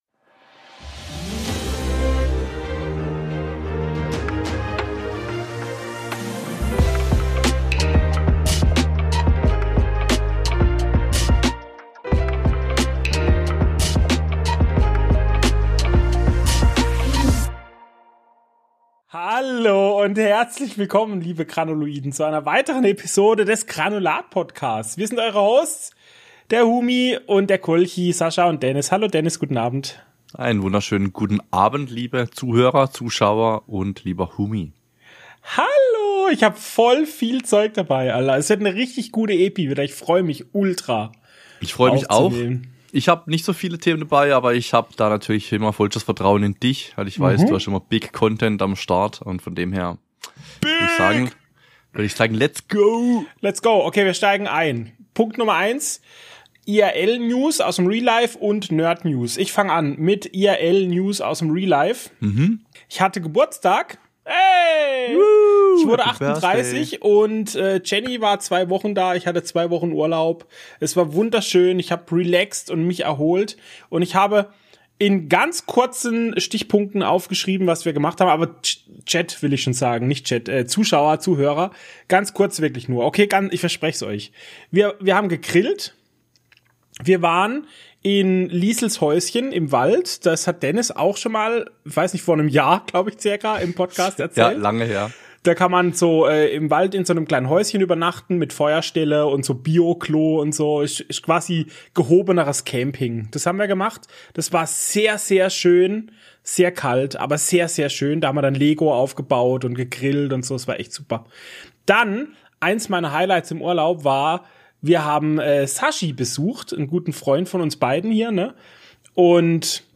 Wir sind zwei Freunde, die sich für alles begeistern, was mit Kino, Serien, Gaming und der Nerd- und Internetkultur zu tun hat. In unserem Podcast teilen wir unsere Meinungen zu aktuellen Themen, besprechen Neuheiten und Trends und geben Empfehlungen zu allem, was uns begeistert.